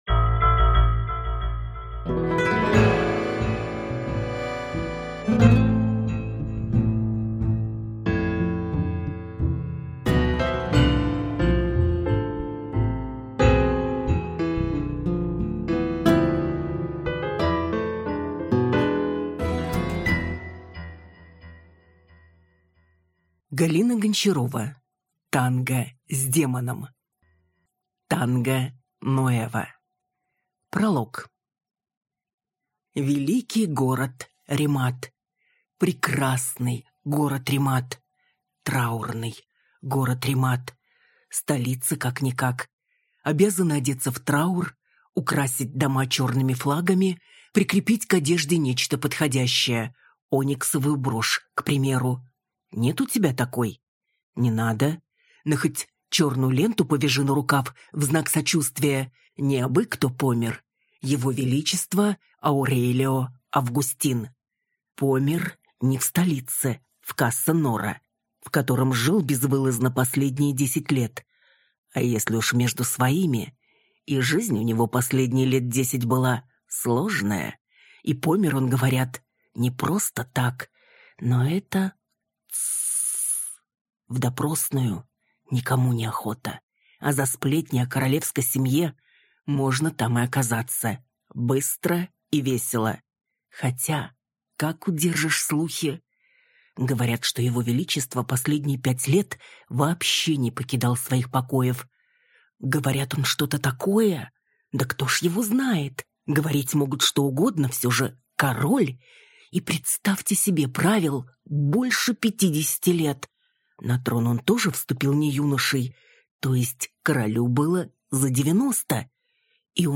Аудиокнига Танго с демоном.